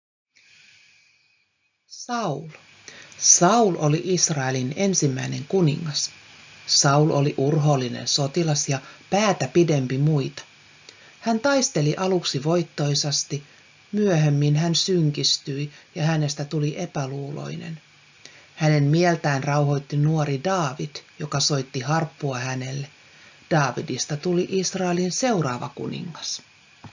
Lue itse tai kuuntele opettajan lukevan.